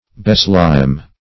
beslime - definition of beslime - synonyms, pronunciation, spelling from Free Dictionary
Beslime \Be*slime"\